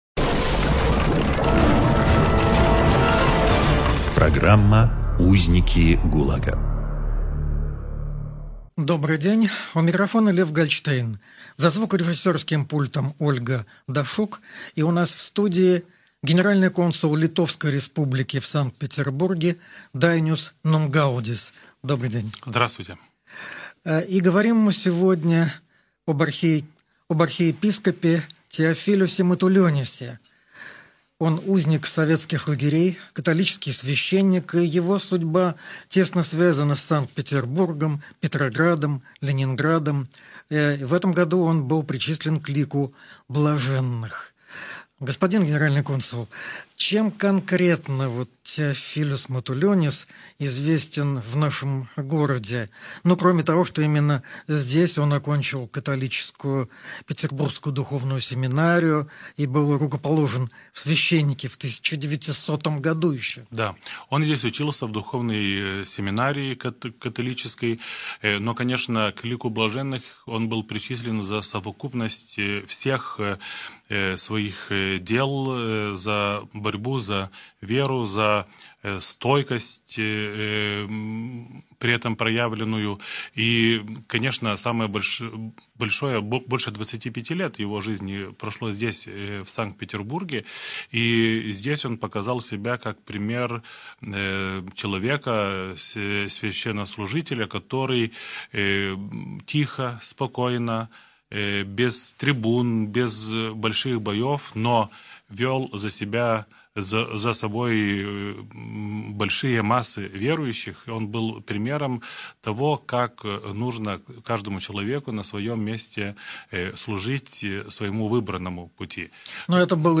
В гостях: Дайнюс Нумгаудис, генеральный консул Литовской Республики в Санкт-Петербурге (в 2015–2020 г.)